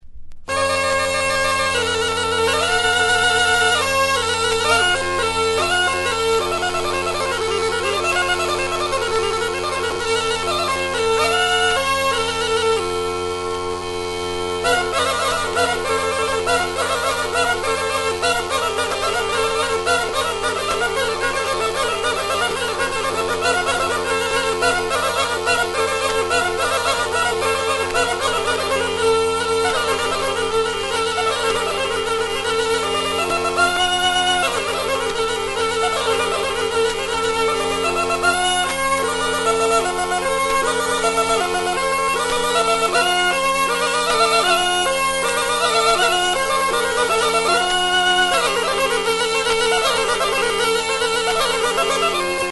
Aérophones -> Anches -> Simple battante (clarinette)
LAUNEDDAS; MEDIANA PIPIA; MEDIANA SCIUTA
Klarinete hirukoitza da. Bi tutuk doinua ematen dute, eta hirugarrenak (luzeenak eta zulorik ez duenak) nota pedala ematen du.